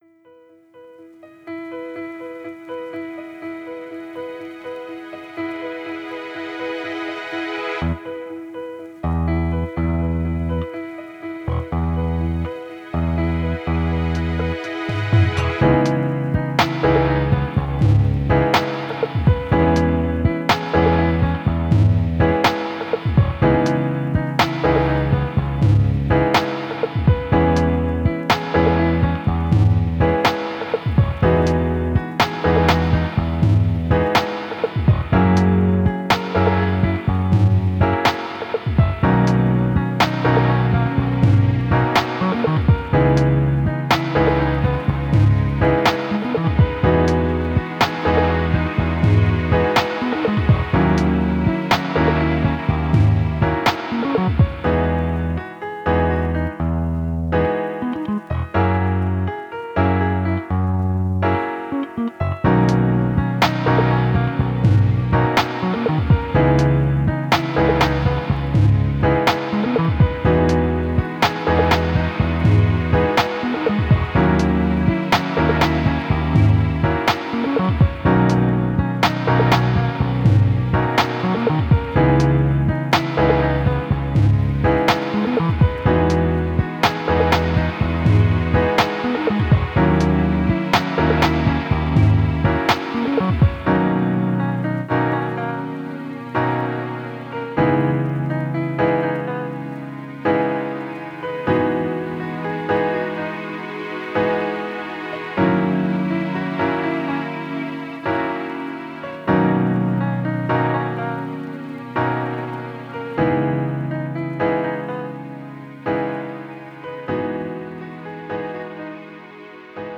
Lovey dubey touchy feely...